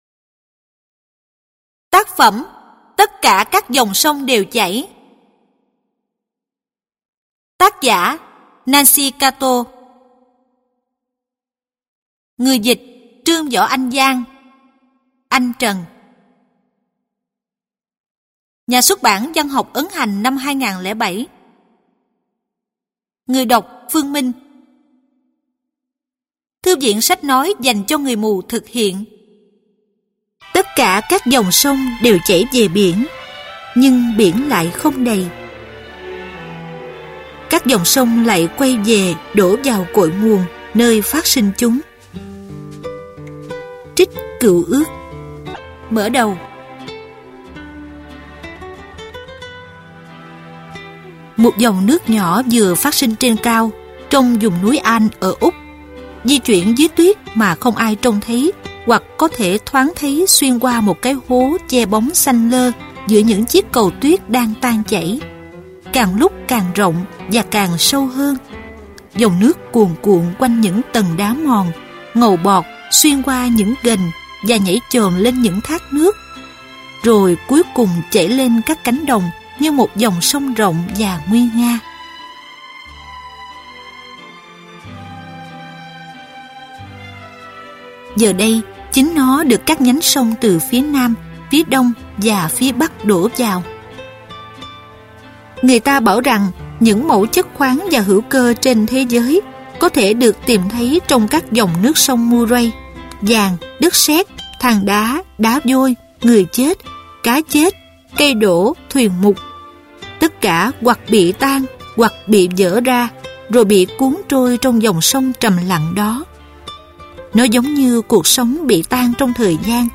Sách nói | Tất cả các dòng sông đều chảy